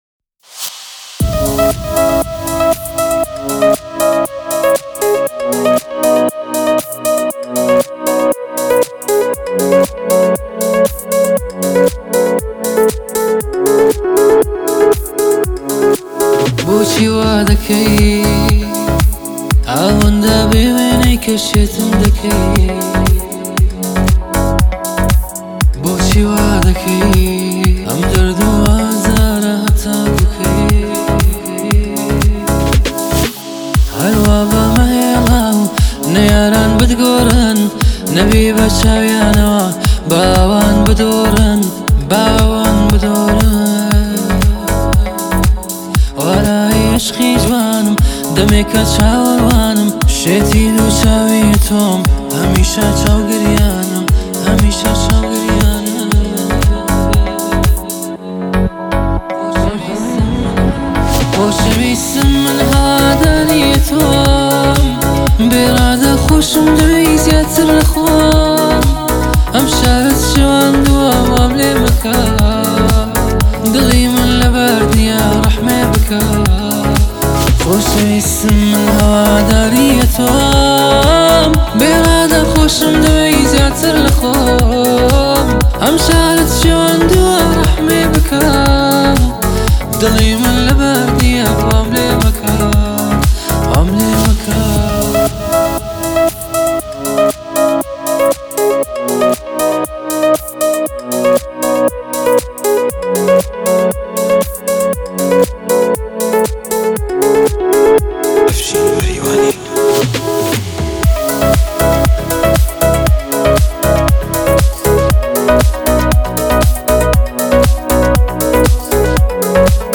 آهنگ کردی پاپ